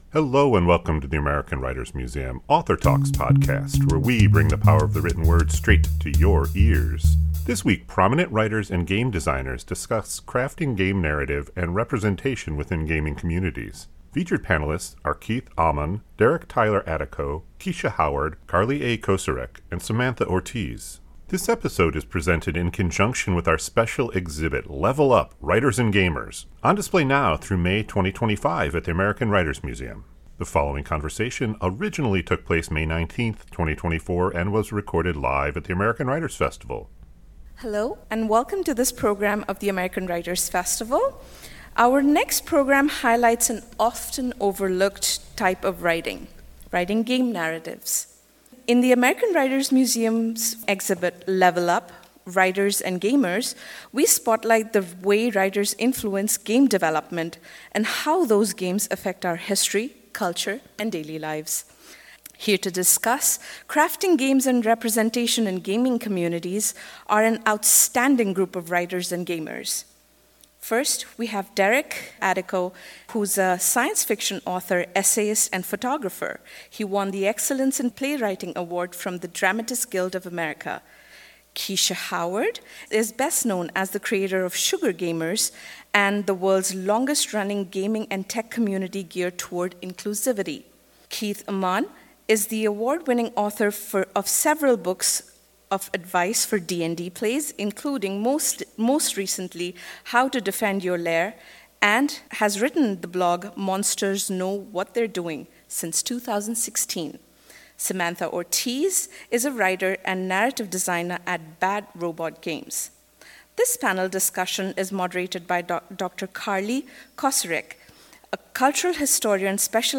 This week, prominent writers and game designers discuss crafting game narrative and representation within gaming communities.